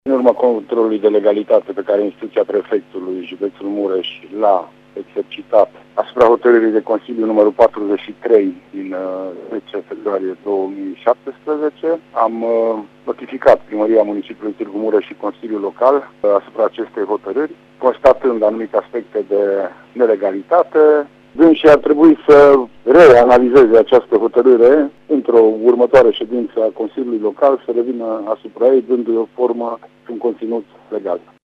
Prefectul judeţului Mureş, Lucian Goga, a declarat, pentru RTM, că a notificat ultima Hotărâre a Consiliului Local Tîrgu Mureş, la care este ataşat un proiect al reţelei şcolare pe 2017-2018.